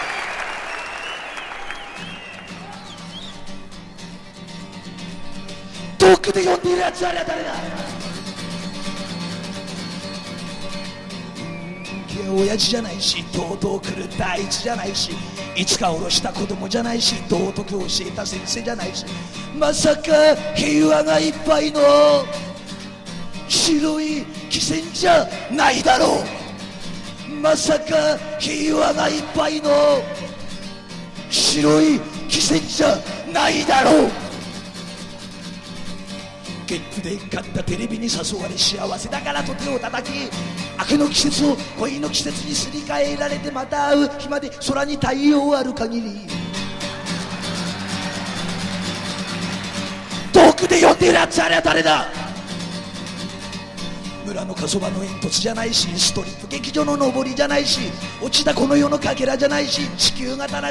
1972年のライブ編集盤。